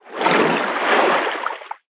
File:Sfx creature featherfish swim slow 03.ogg - Subnautica Wiki
Sfx_creature_featherfish_swim_slow_03.ogg